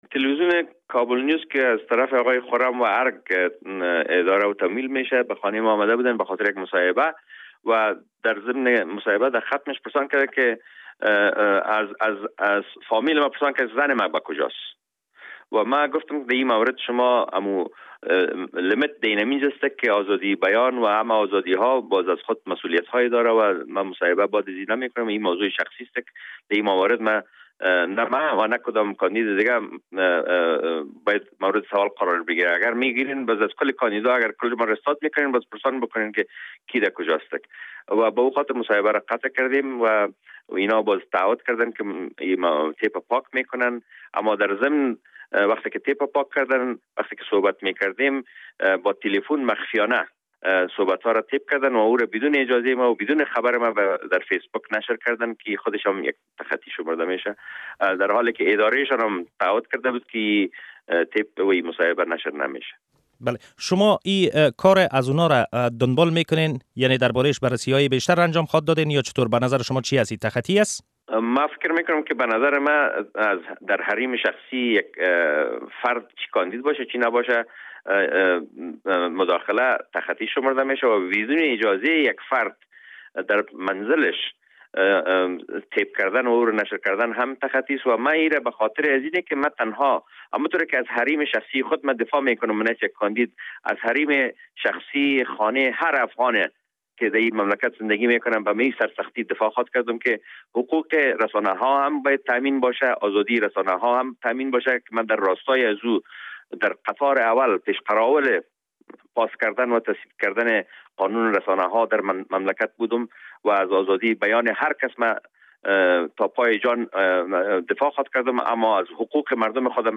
مصاحبه با داوود سلطانزوی در مورد ادعای اهانت به خبرنگاران